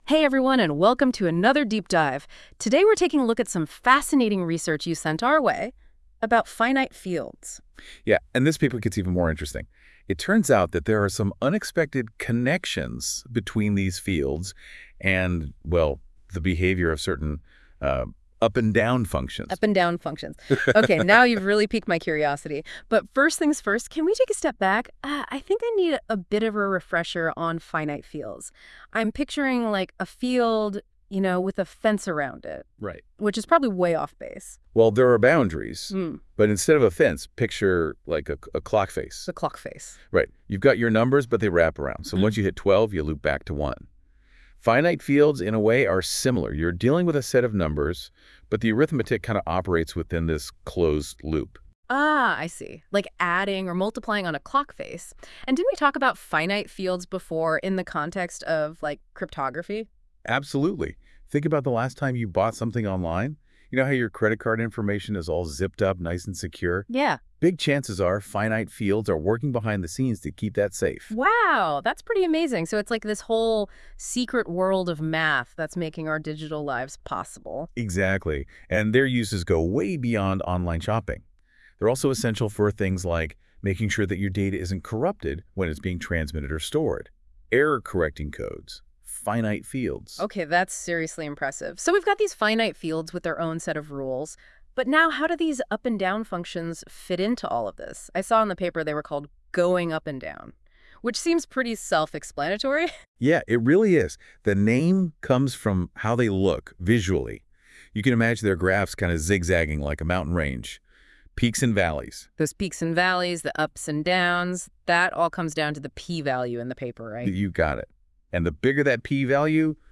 Mi artículo arXiv y el podcast generado en notebookml (en inglés)